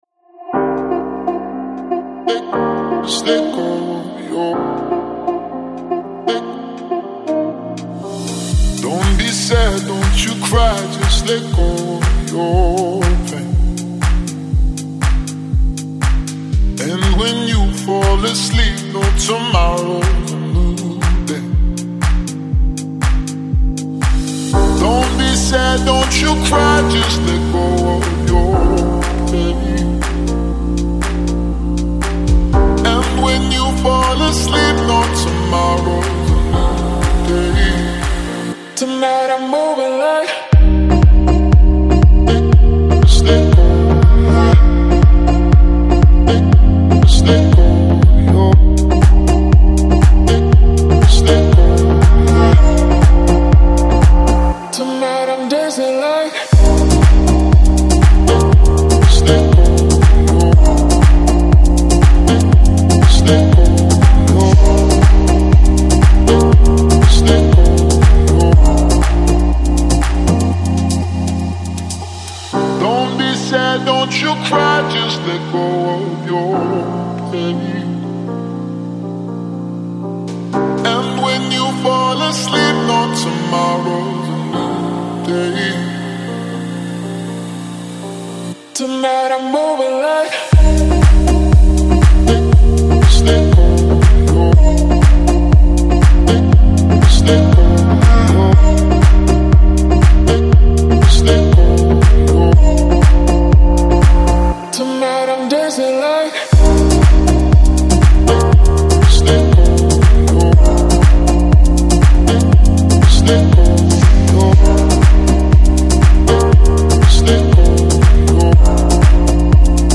Жанр: Electronic, Lounge, Chill House, Pop